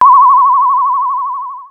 Medicscanner3.wav